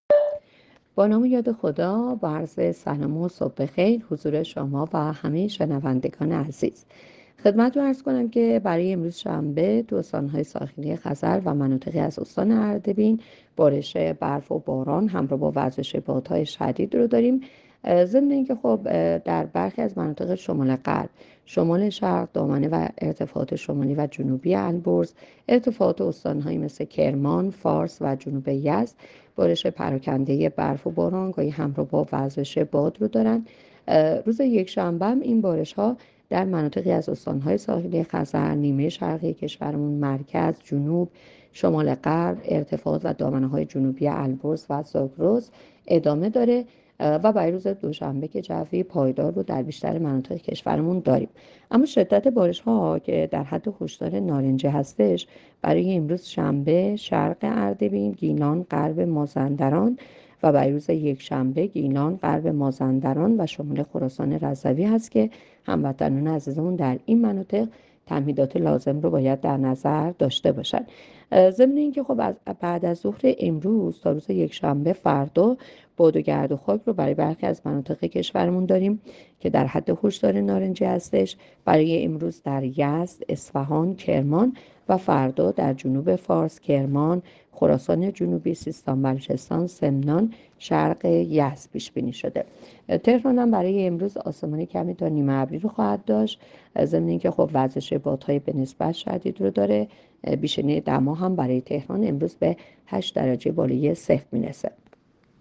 گزارش رادیو اینترنتی پایگاه‌ خبری از آخرین وضعیت آب‌وهوای ۱۱ اسفند؛